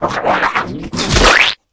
spit.ogg